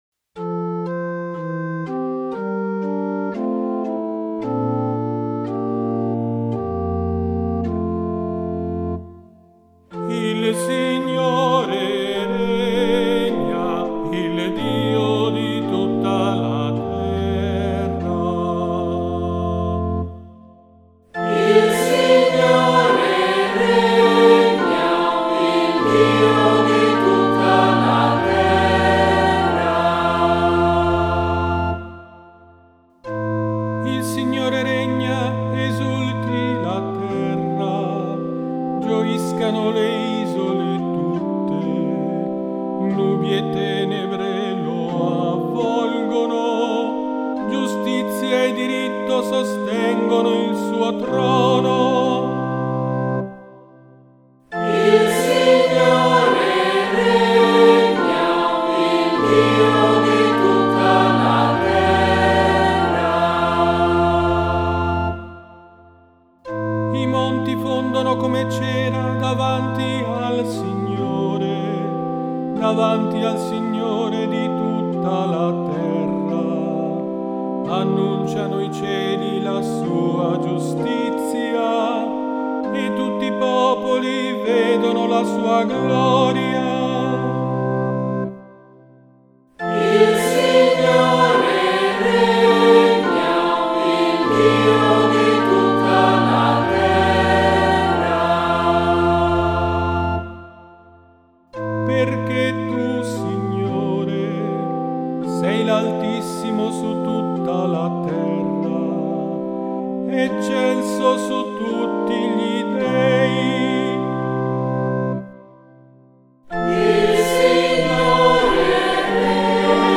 Canto liturgico Liturgia Messa domenicale Musica sacra
Salmo Responsoriale